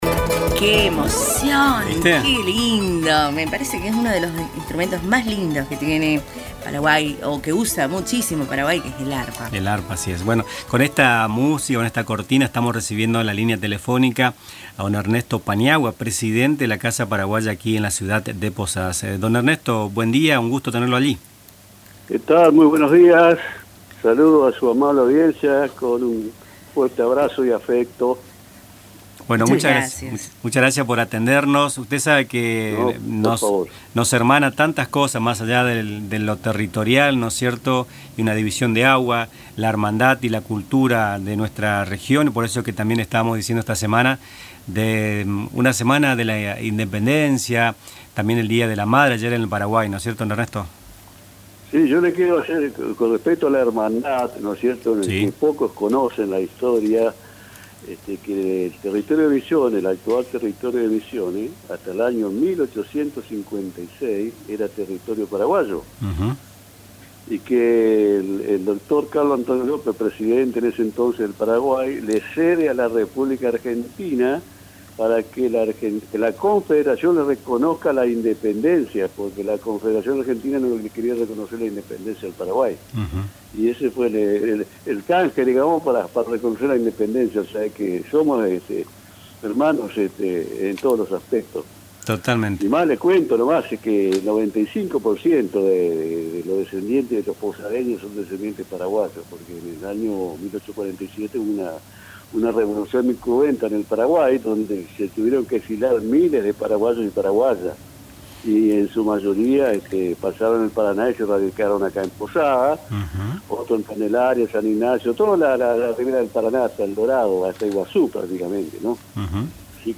Nuestras Mañanas, entrevistamos